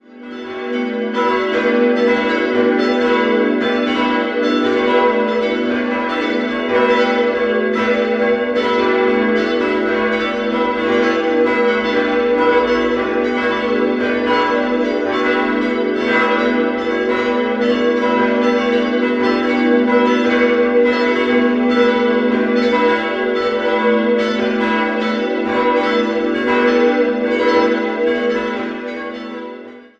5-stimmiges Geläute: g'-h'-cis''-e''-fis'' Die Glocken 2 und 4 wurden im Jahr 2015 von der Firma Rincker in Sinn gegossen.